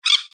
دانلود صدای پرنده 8 از ساعد نیوز با لینک مستقیم و کیفیت بالا
جلوه های صوتی